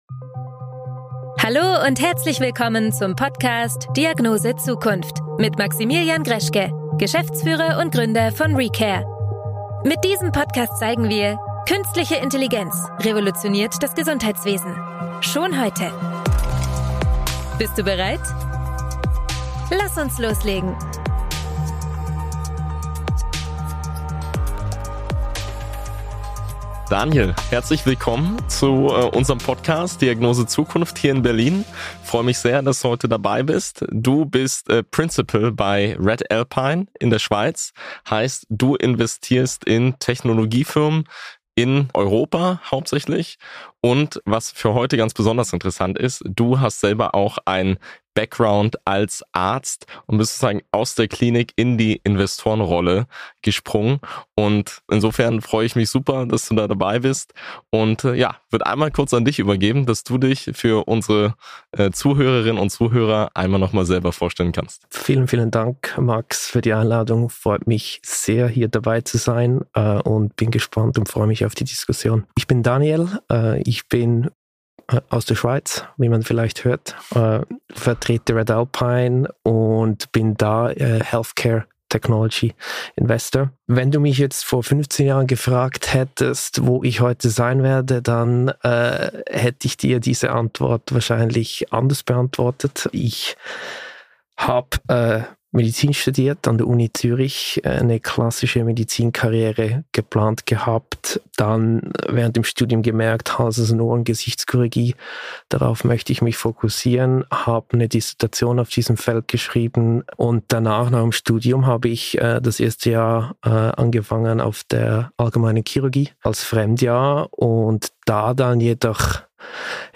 Investments in Healthcare Technology – Interview